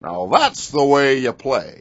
gutterball-3/Gutterball 3/Commentators/Baxter/wack_thatshowyouplay.wav at d85c54a4fee968805d299a4c517f7bf9c071d4b9
wack_thatshowyouplay.wav